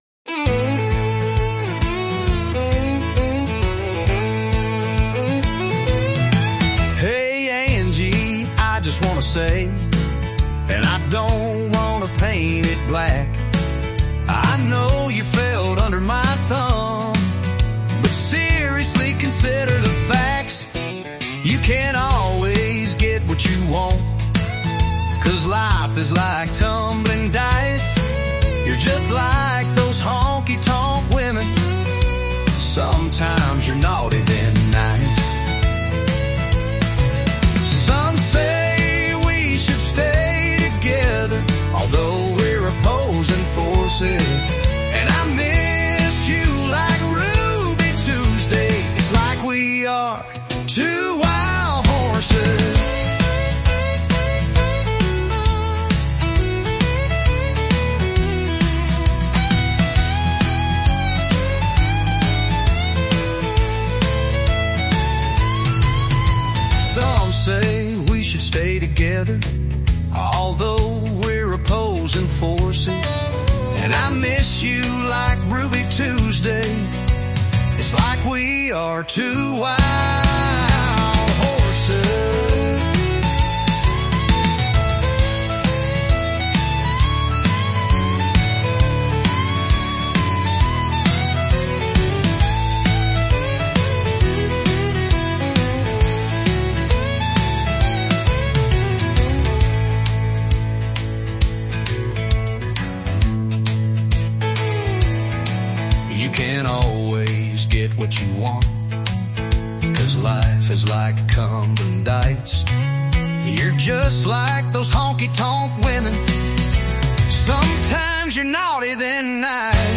Really like the poem set to country sound.